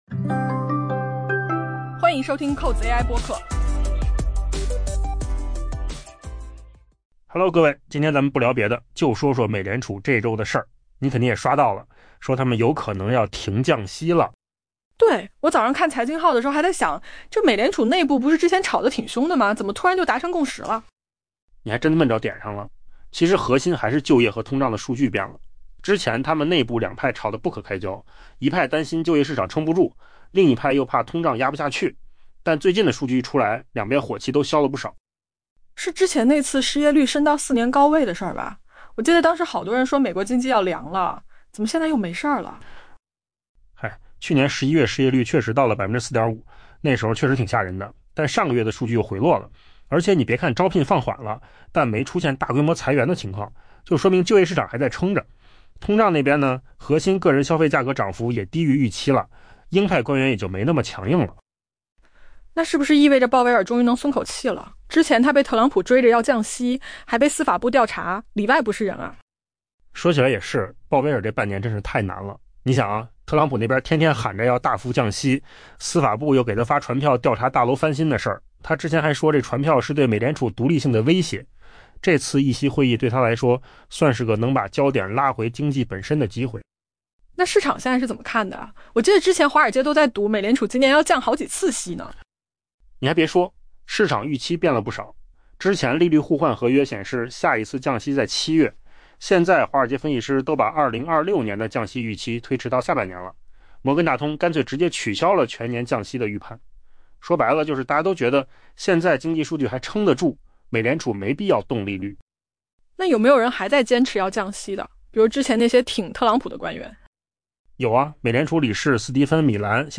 AI 播客：换个方式听新闻 下载 mp3 音频由扣子空间生成 由于就业市场趋于稳定，美联储在经历了数月的分歧后，内部达成了一定程度的共识，因此市场普遍预期美联储将在本周停止降息周期。